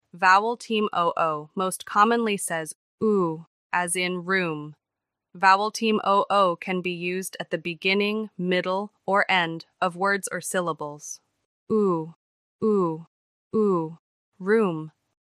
Vowel team OO most commonly says /oo/, as in “room”.